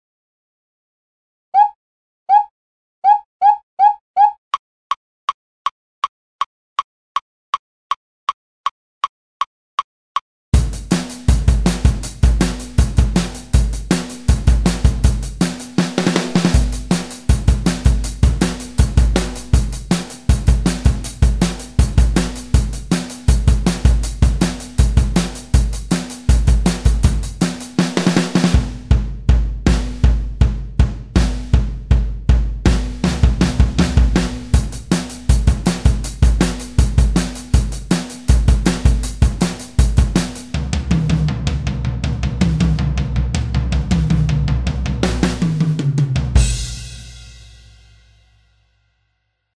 schnell